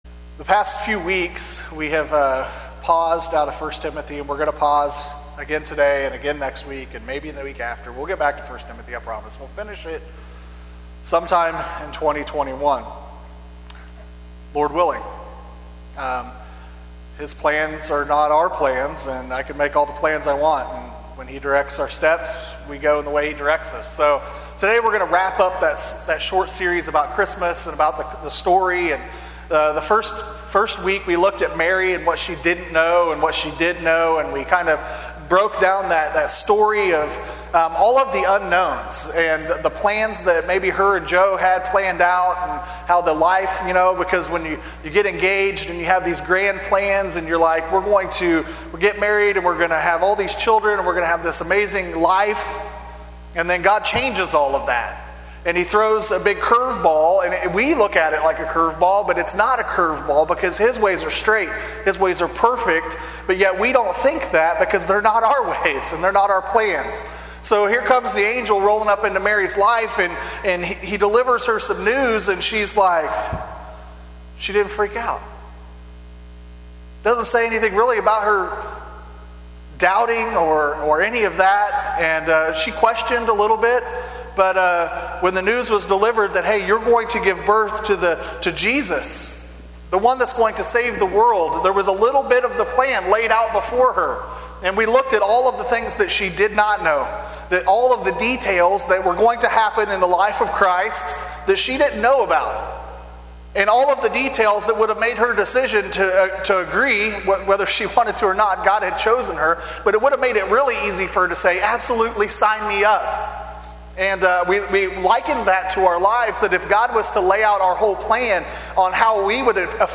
In this sermon on Luke chapter 2